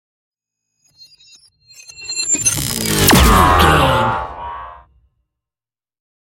Sci fi shot whoosh to hit
Sound Effects
heavy
intense
aggressive
hits